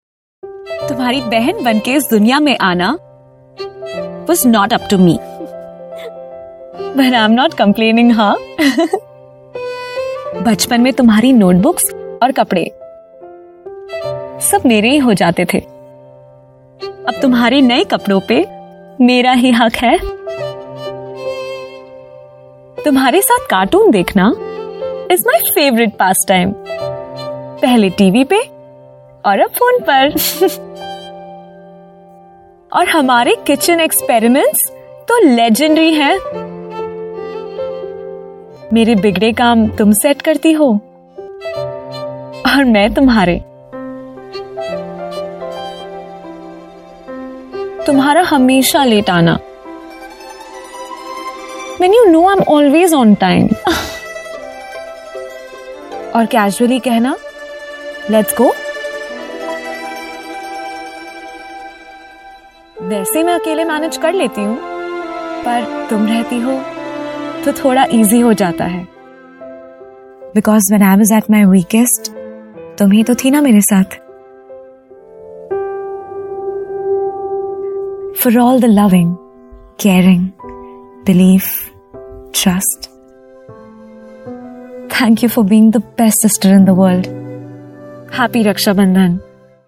Female
She has a sweet, soft, soothing as well as deep and husky voice texture.
Television Spots
Conversational Friendly Emotion
All our voice actors have professional broadcast quality recording studios.
0421Hinglish__Natural__friendly_.mp3